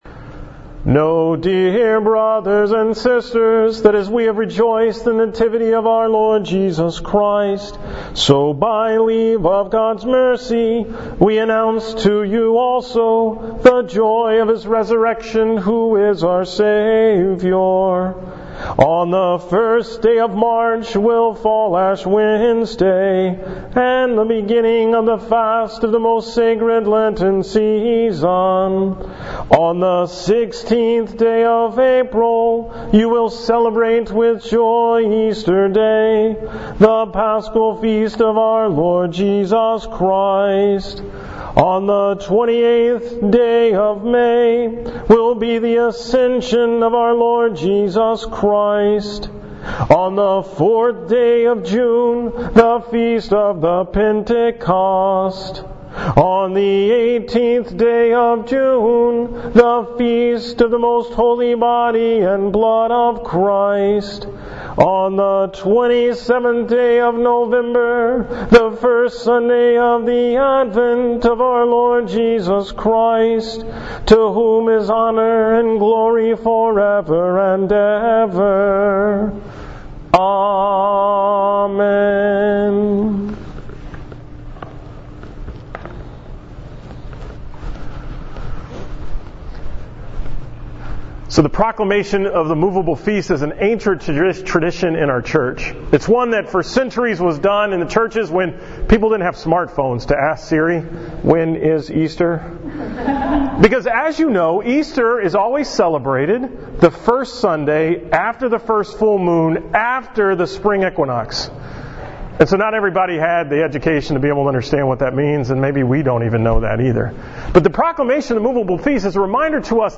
From the Upper Room day of prayer for women discerning a call to religious life on January 8, 2017